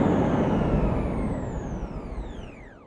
autopilotstop.ogg_1c4edf31